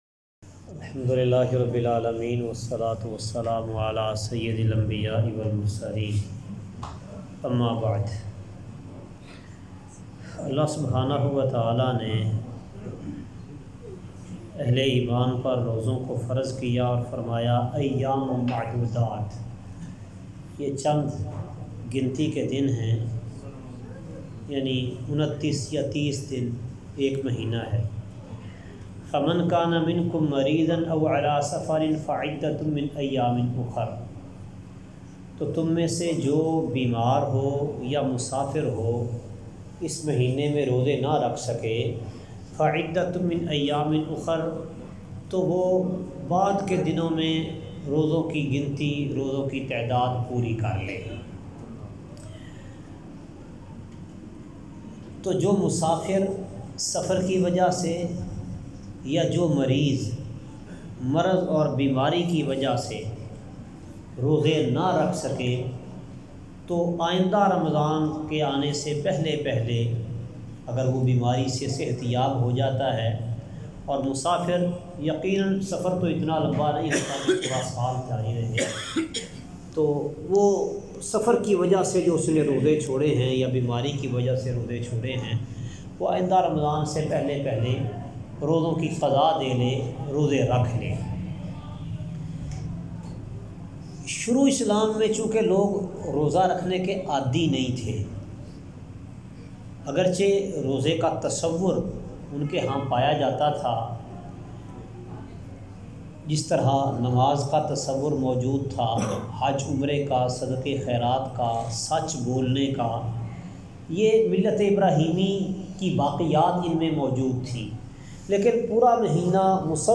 روزوں کی قضا اور فدیہ درس کا خلاصہ کسی عذر کی وجہ سے رمضان میں روزہ نہ رکھا جا سکے تو بعد میں اسکی قضاء دی جائے گی۔